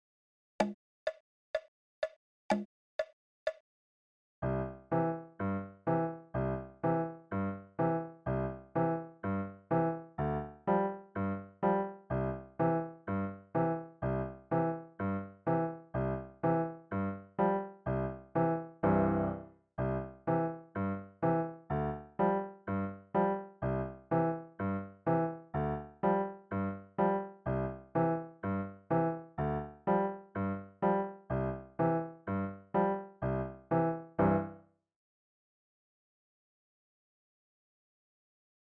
acc.